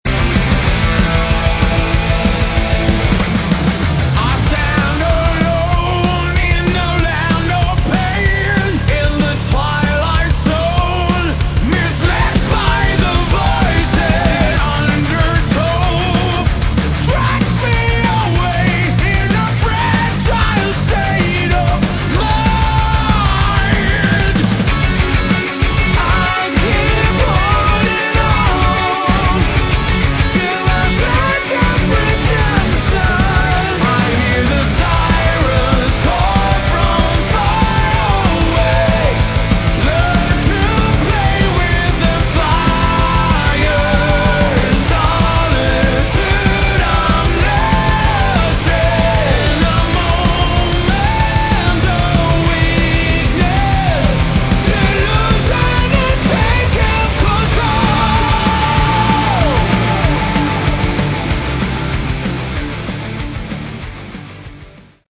Category: Melodic Metal
vocals
guitars, bass, keys, drums